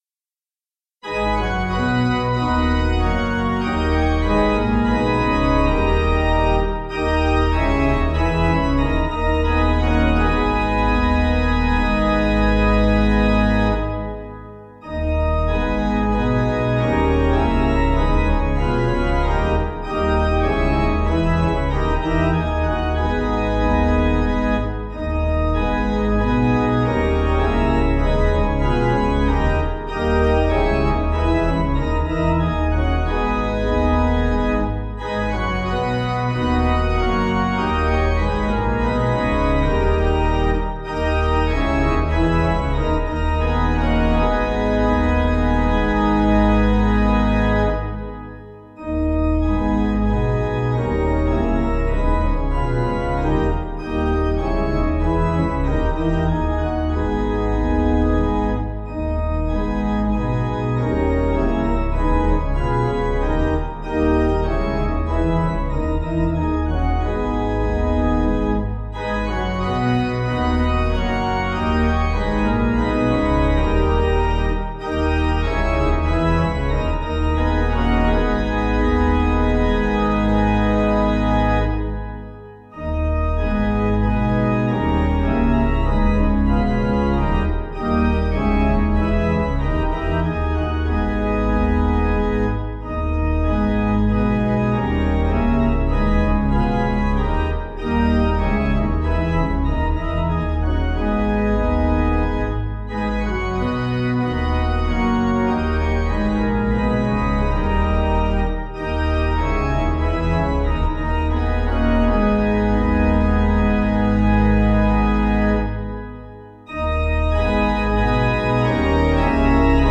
Organ
(CM)   4/G#m